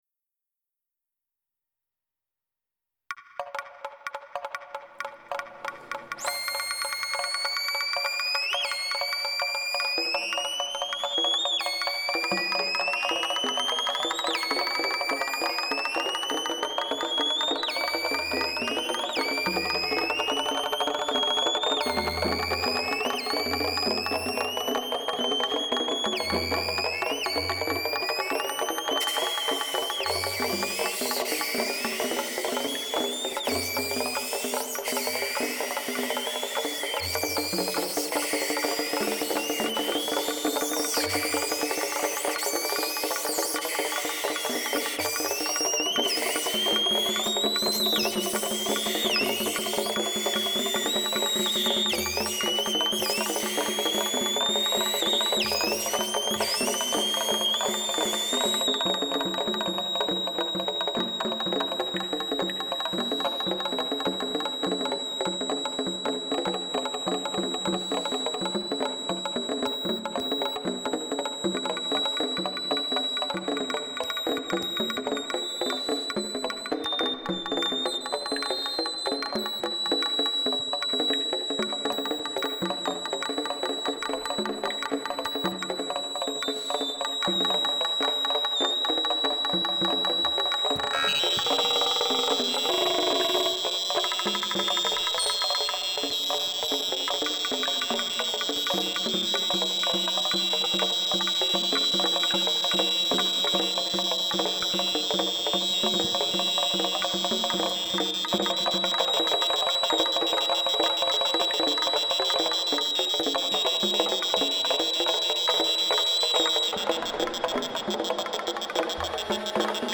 improvised prepared Sax